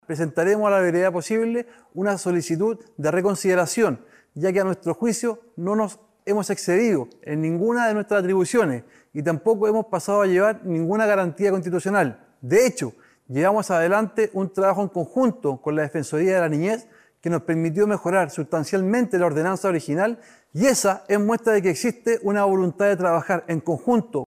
De igual forma, el jefe comunal indicó que presentarán una solicitud de “reconsideración porque a nuestro juicio no nos hemos excedido en ninguna de nuestras atribuciones y tampoco hemos pasado a llevar ninguna garantía constitucional”.